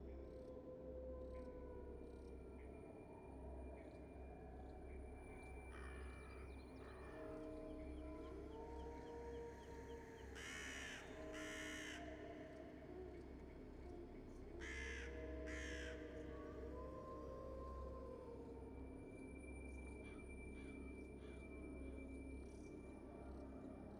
amb_sqgame_loop.wav